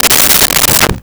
Dresser Drawer Close 04
Dresser Drawer Close 04.wav